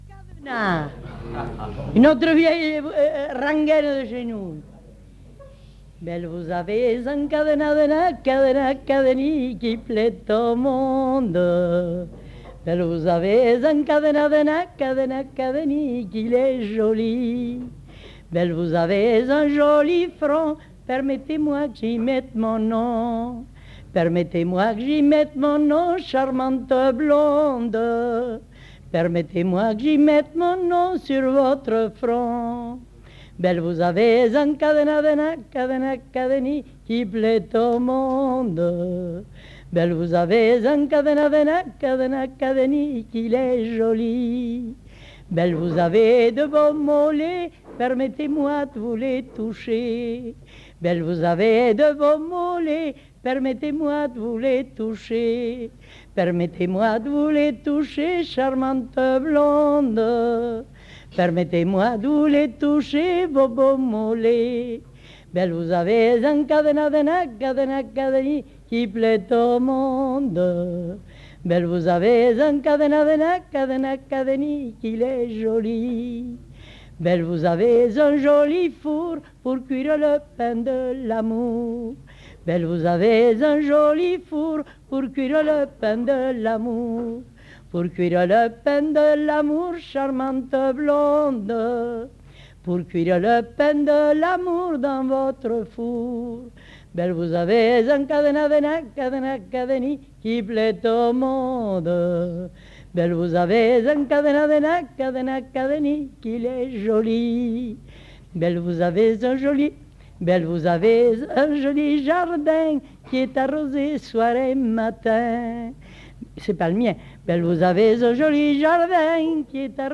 Aire culturelle : Viadène
Lieu : Orbe
Genre : chant
Effectif : 1
Type de voix : voix de femme
Production du son : chanté